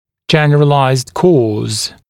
[‘ʤen(ə)r(ə)laɪzd kɔːz][‘джэн(э)р(э)лайзд ко:з]общая причина